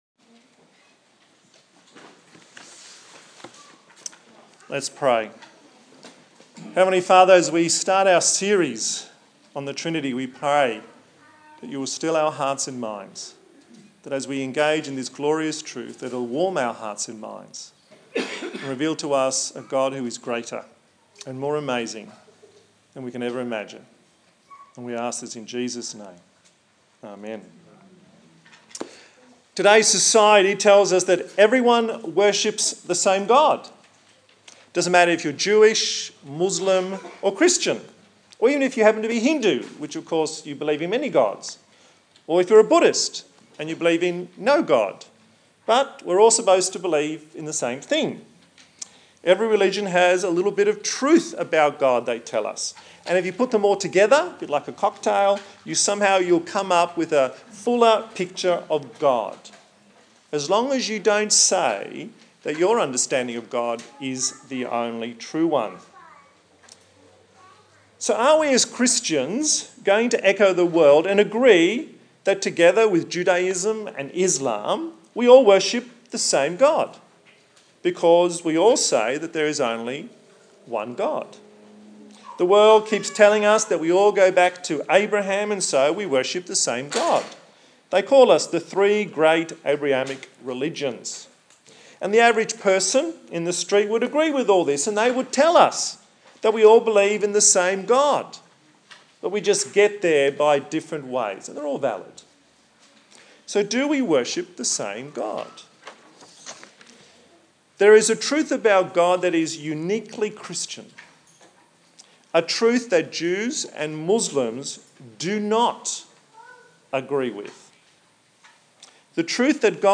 The first in a sermon series on the Trinity.
Deuteronomy 4:32-40 Service Type: Sunday Morning The first in a sermon series on the Trinity.